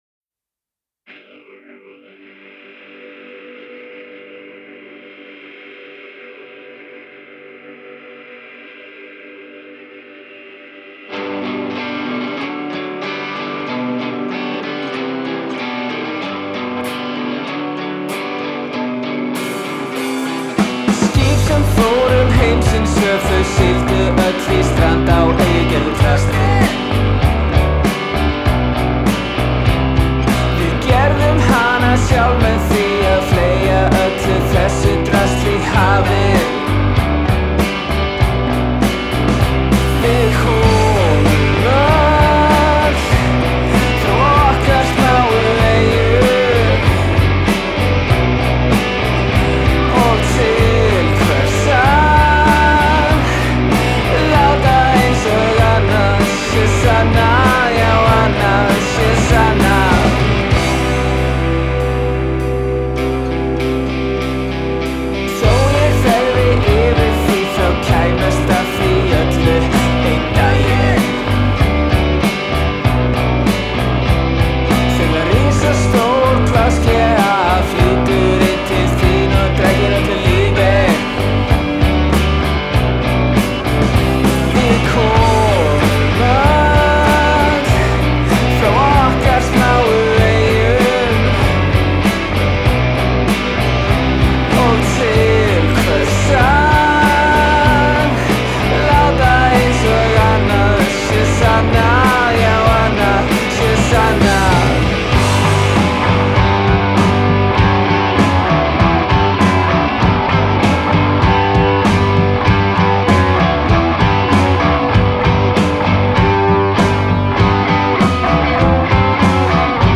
guitar, bass and singing
drummer